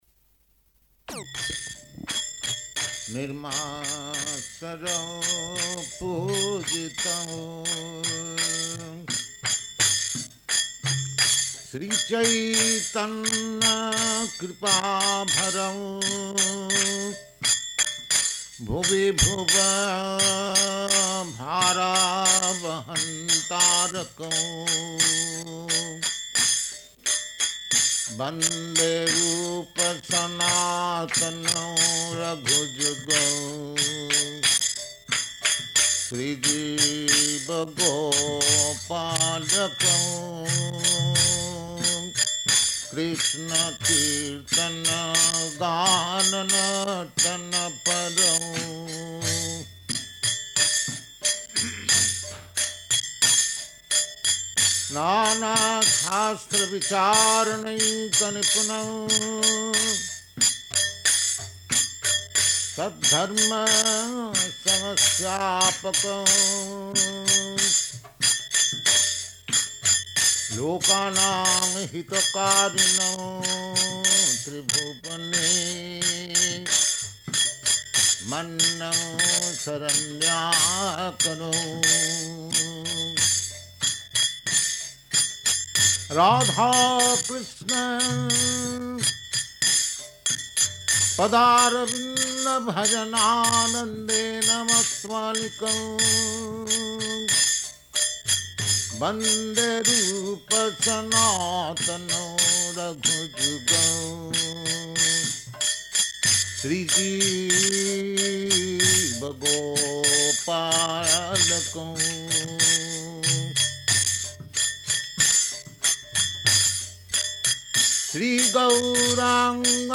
Type: Lectures and Addresses
Location: Seattle
[Sings: Śrī Śrī Ṣaḍ-gosvāmy-aṣṭaka , kīrtana ]